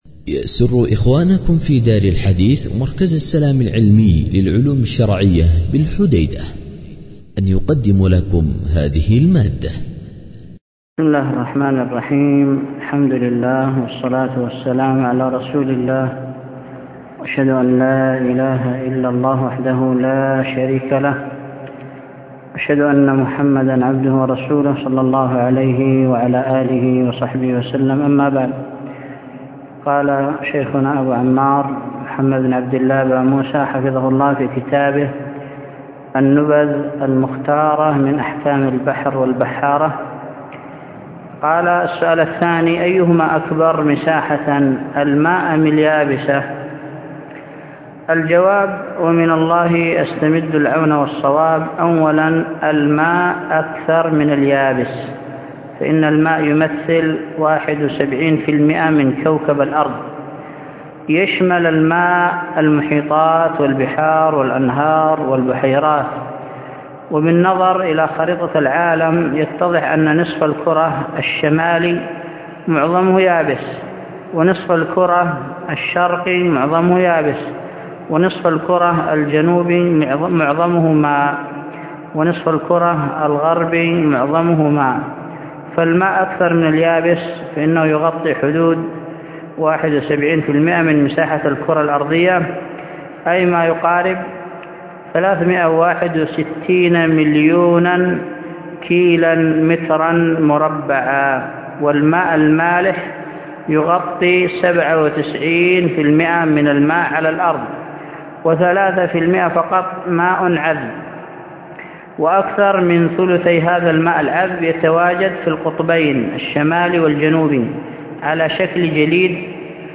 الدرس الثاني